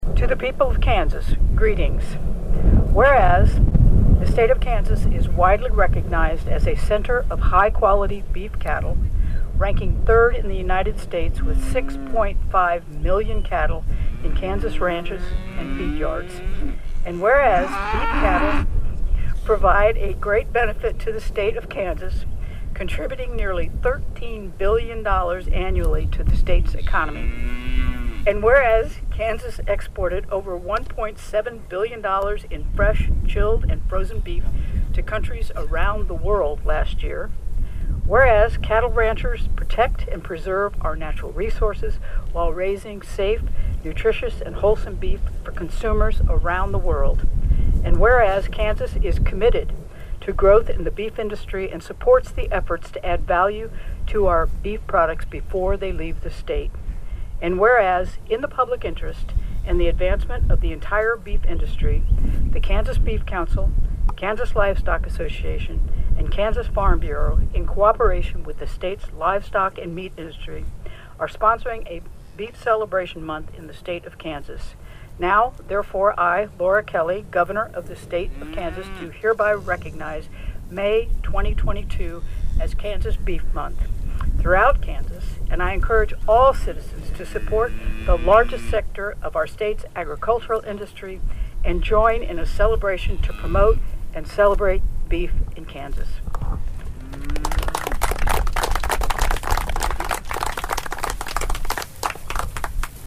Kansas Gov. Laura Kelly was in rural Riley County Thursday to proclaim Beef Month, which continues through the end of May.
0512-Kelly-Proclamation.mp3